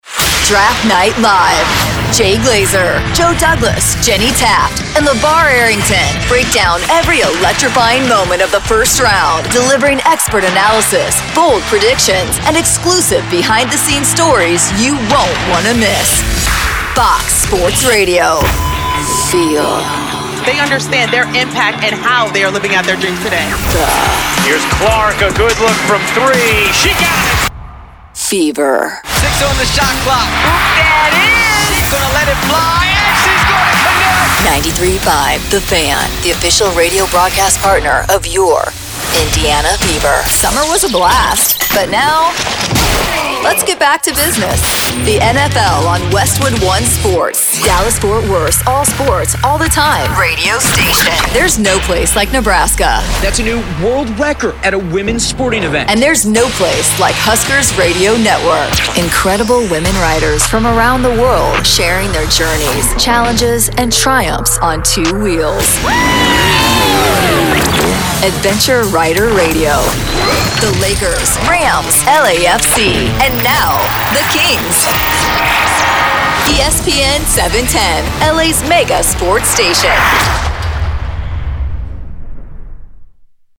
All of our contracted Talent have broadcast quality home recording studios.